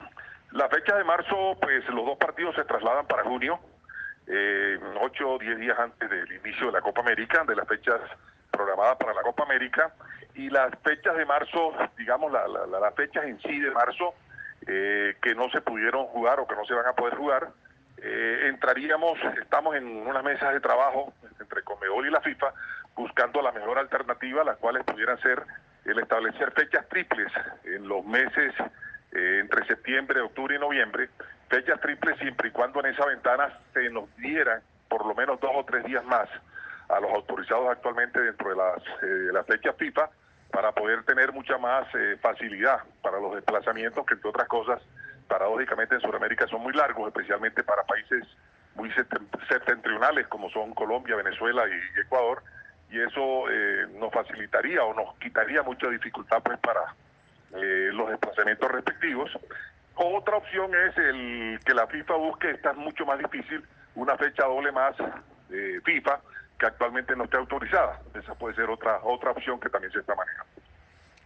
en diálogo con Planeta Fútbol de Antena 2